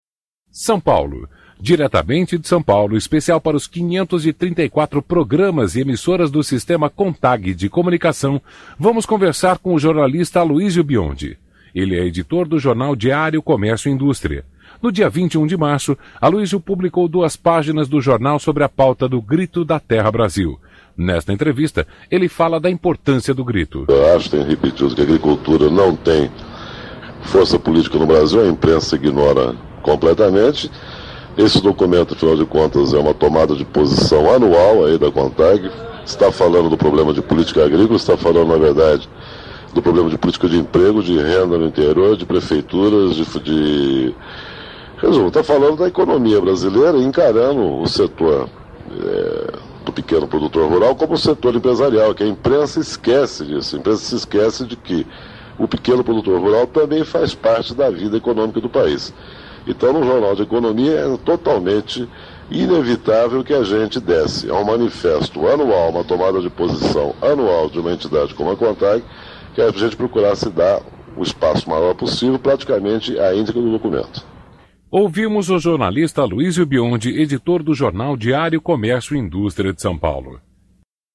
A pauta dos agricultores em 1997 Programa A Voz da Contag Edição 207, 26/03/1997 Nesta pequena chamada do programa A Voz da Contag , produzida pela Oboré , o jornalista Aloysio Biondi, então editor do jornal Diário Comércio e Indústria (DCI), é entrevistado após publicar duas páginas sobre a pauta de reivindicações do grupo no ano de 1997. Biondi relaciona problema de política agrícola com a questão do trabalho e de toda economia.